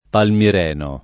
DOP: Dizionario di Ortografia e Pronunzia della lingua italiana
vai all'elenco alfabetico delle voci ingrandisci il carattere 100% rimpicciolisci il carattere stampa invia tramite posta elettronica codividi su Facebook palmireno [ palmir $ no ] etn. stor.